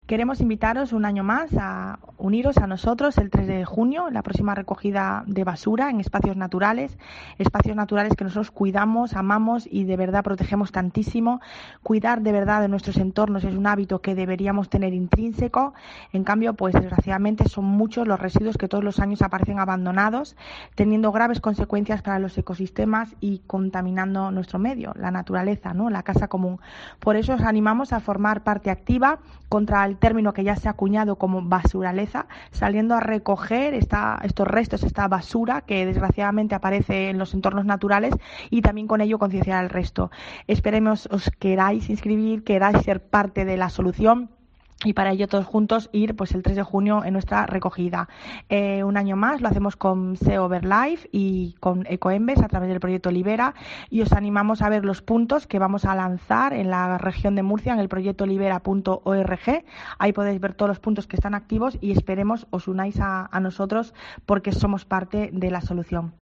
Mari Cruz Ferreria, directora general del Medio Natural